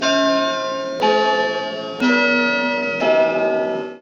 countdown.39b8ce06.mp3